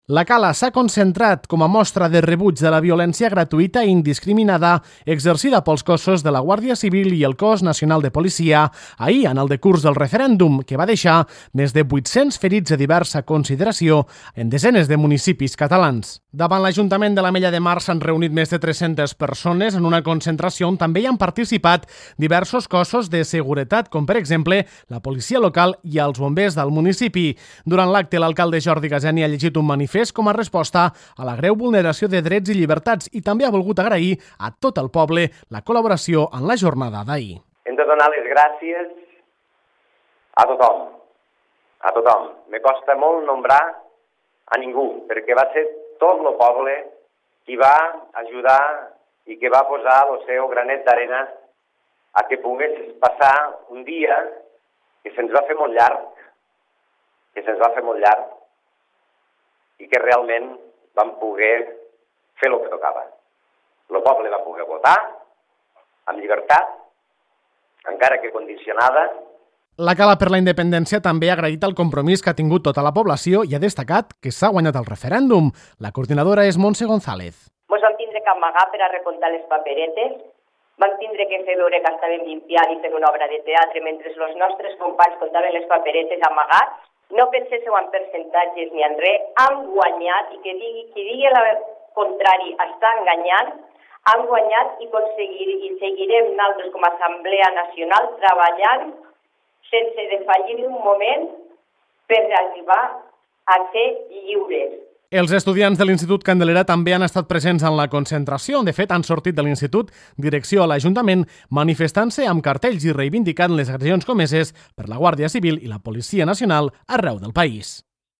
Més de 300 persones s’han manifestat a les portes de l’Ajuntament, aquest dilluns al migdia, en contra de l’actuació de l’Estat espanyol per impedir la celebració del referèndum de l’1-O.